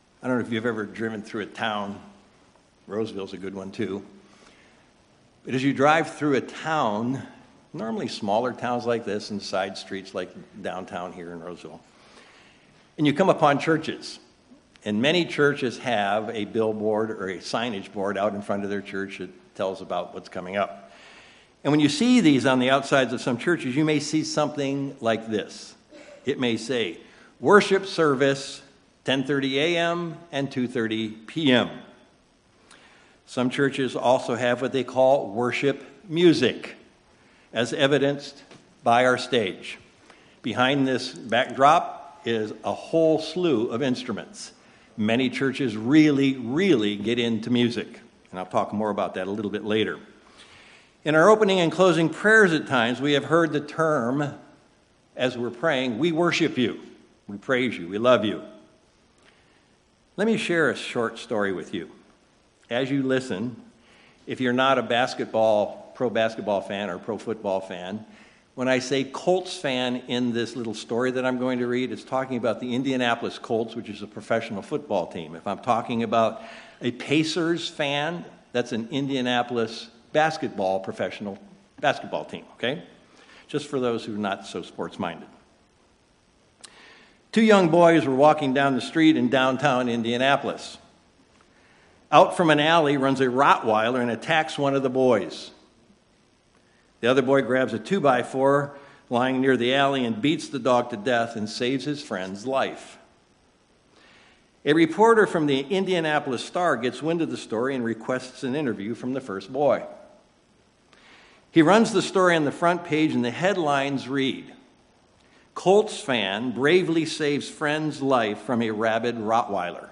We've heard the term worship, we read about it in the Bible, but what is it actually? In this sermon, we will explore what worship and what it is not.